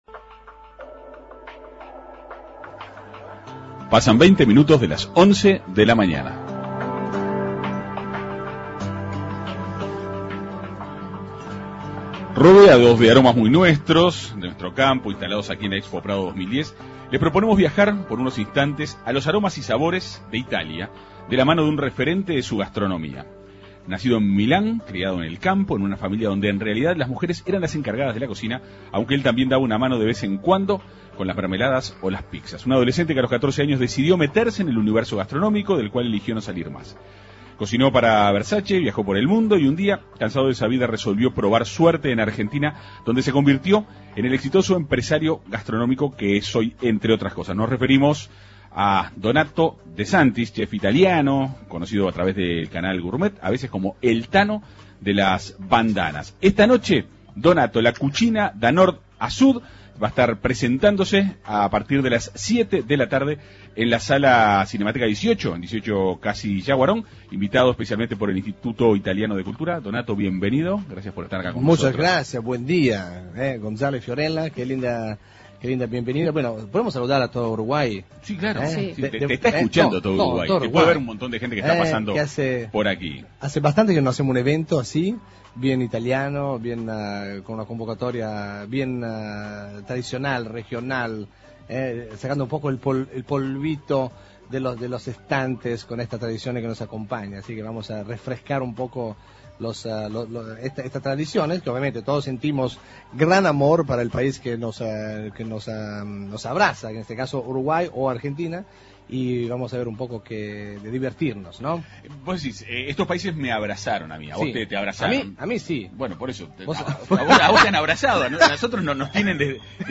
De Santis conversó en la Segunda Mañana de En Perspectiva.